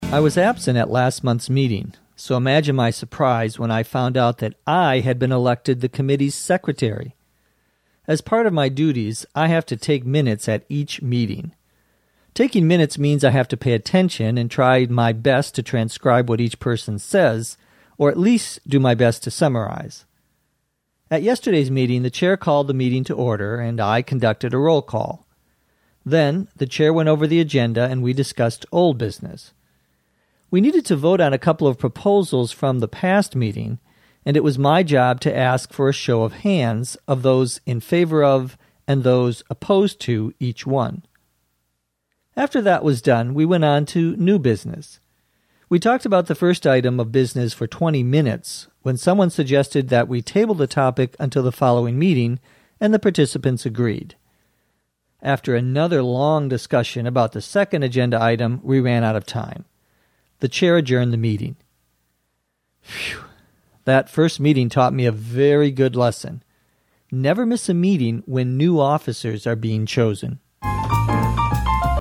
地道美语听力练习:委员会秘书的工作